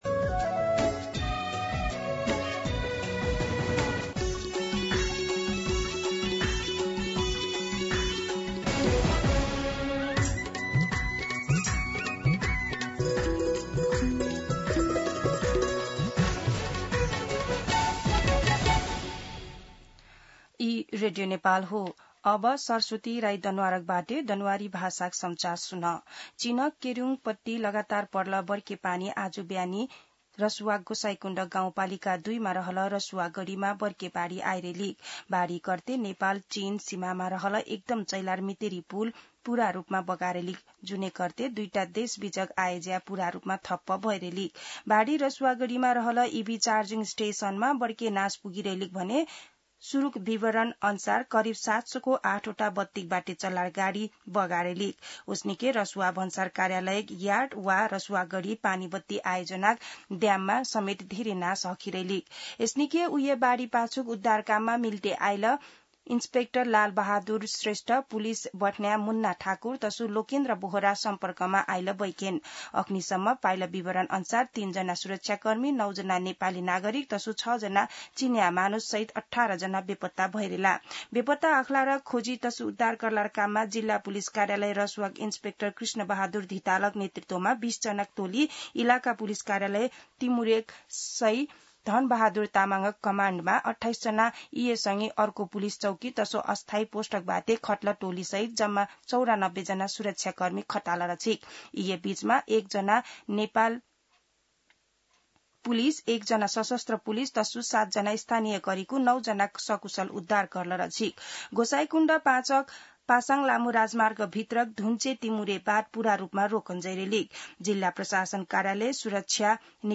दनुवार भाषामा समाचार : २४ असार , २०८२
Danuwar-News-24.mp3